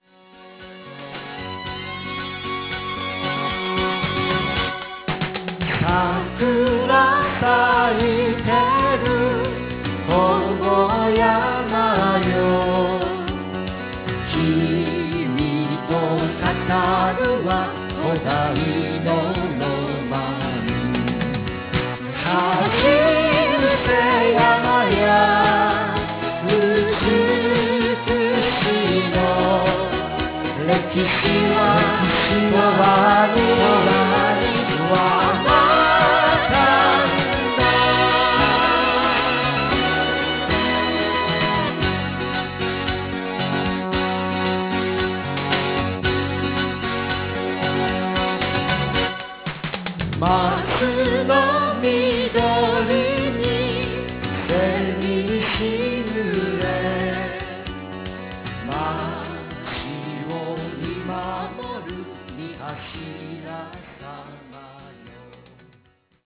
ＣＤバージョン(音声入り)